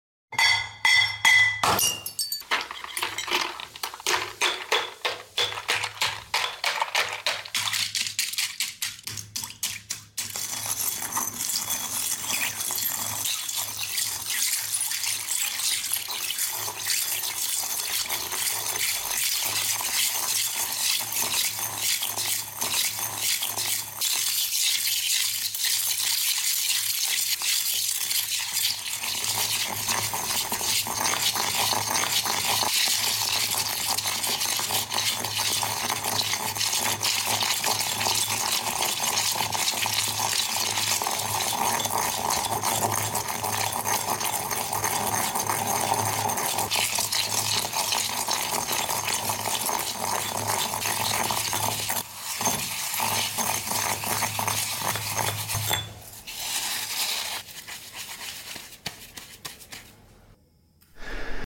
Upload By ASMR videos
Oddlysatisfying crushing soda bottle into